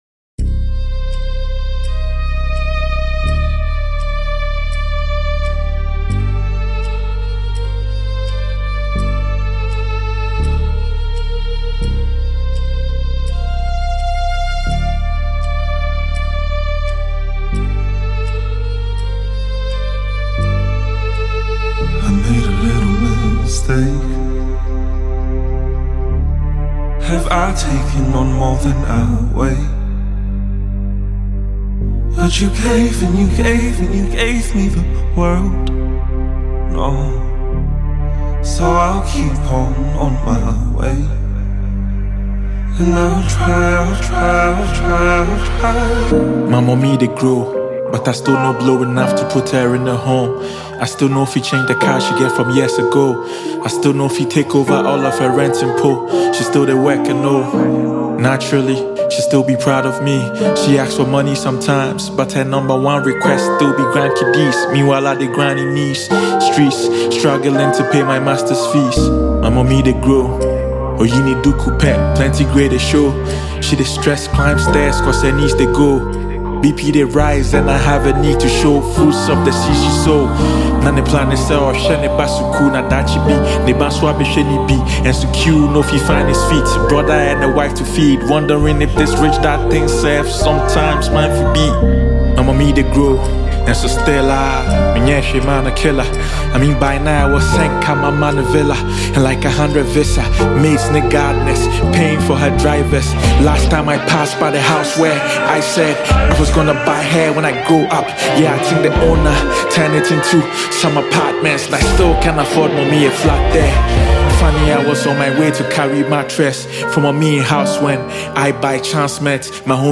calm verses